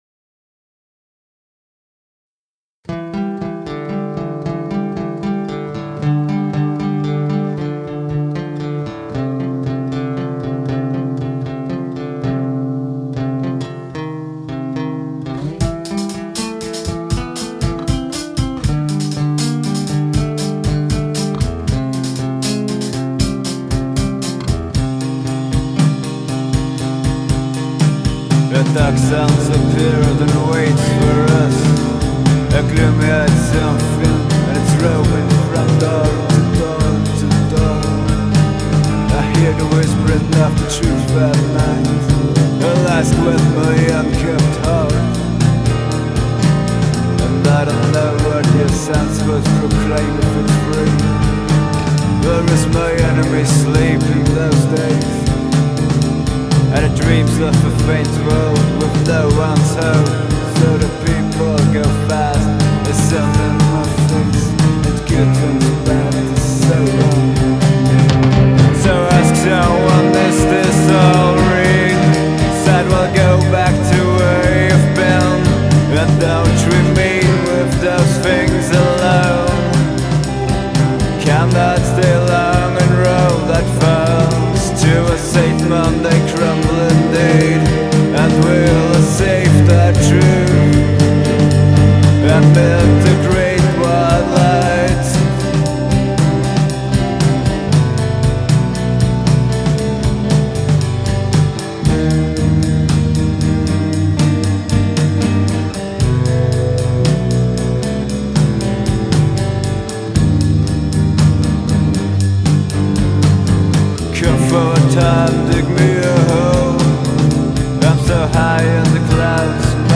cheap recording
from the rehersal room